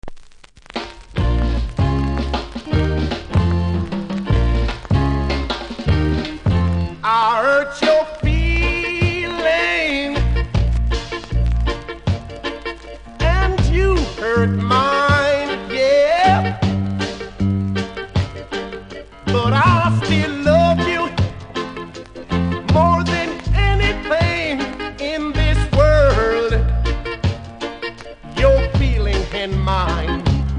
序盤ノイズ感じますので試聴で確認下さい。
中盤派手にキズありで見た目は良くないですが音はそれほど影響されていません。